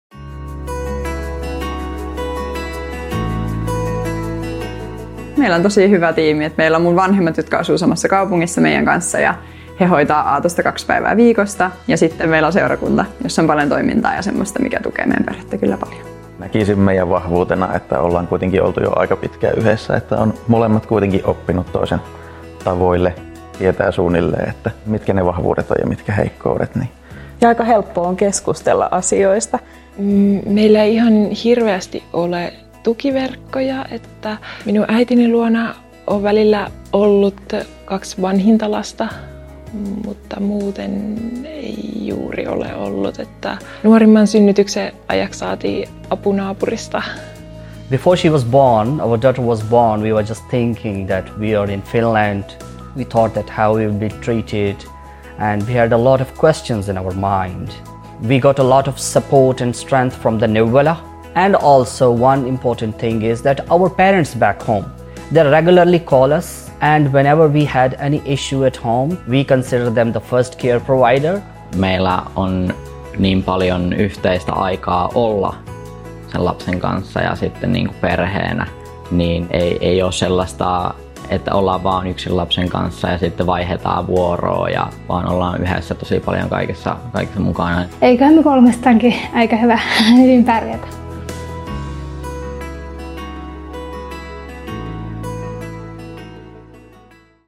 Perheet kertovat omista vanhemmuustiimeistään ja niiden vahvuuksista